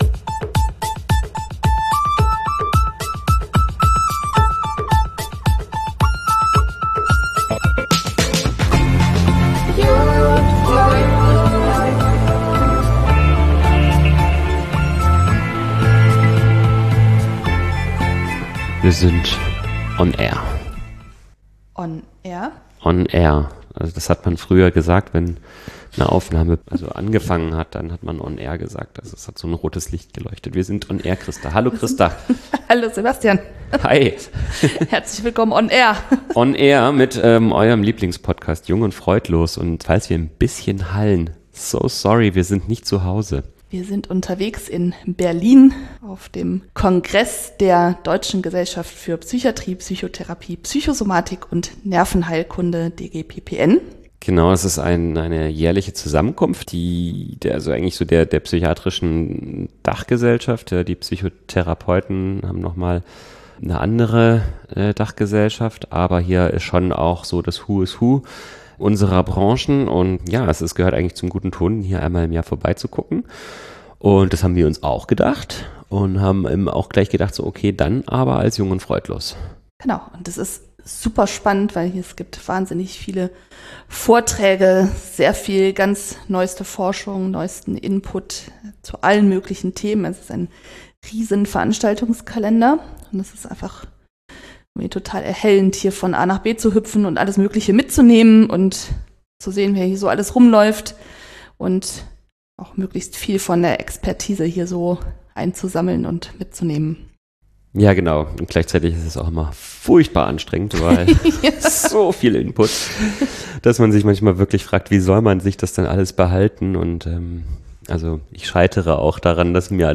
In dieser Sonderfolge unseres Podcasts Jung und Freudlos sind wir live beim DGPPN-Kongress 2025 in Berlin unterwegs und sprechen mit vier führenden Expert:innen über aktuelle Entwicklungen in der Psychiatrie.